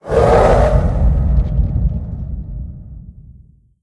spearYellRumbleVoice.ogg